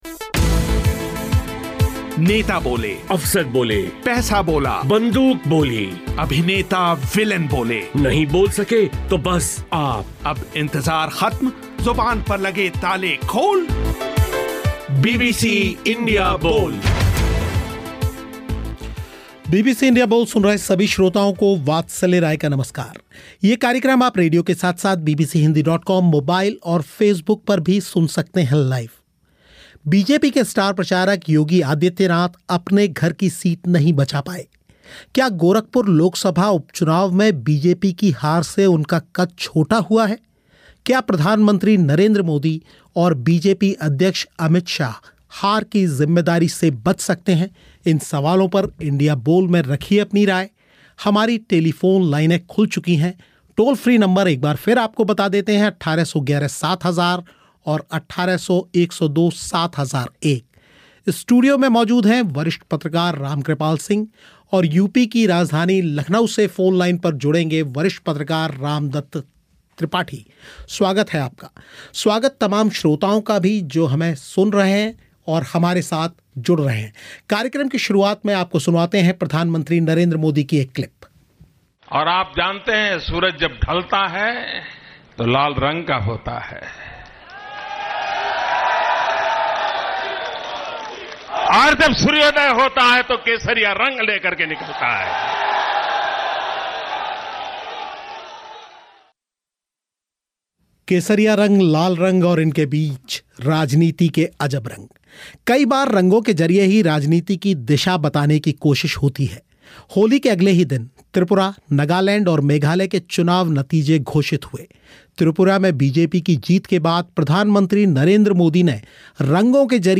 इंडिया बोल में इन्हीं सवालों पर हुई चर्चा